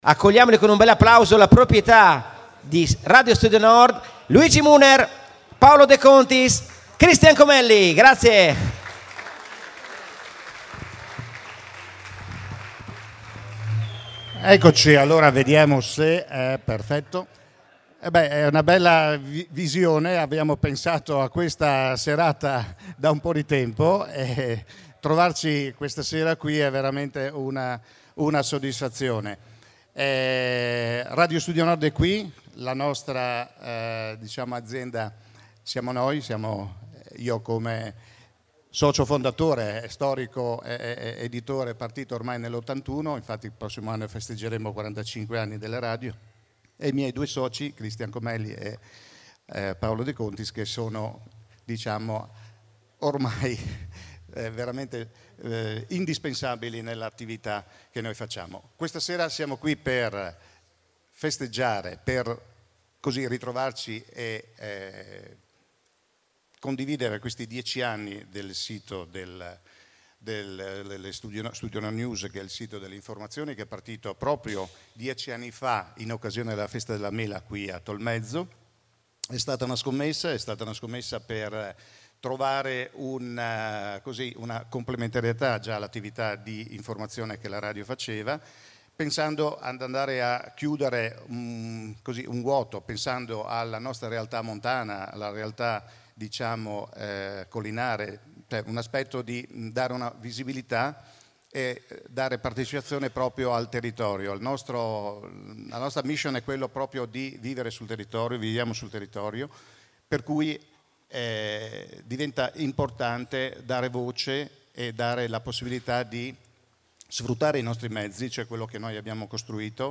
Nell’ambito della Festa della Mela di Tolmezzo, nella Pomis Arena allestita in piazza XX Settembre, Studio Nord News ha festeggiato i suoi primi 10 anni di vita, essendo on line dal 19 settembre 2015. Per l’occasione erano presenti, invitati da Radio Studio Nord, una sessantina di persone tra amministratori pubblici del territorio e rappresentanti del mondo del commercio.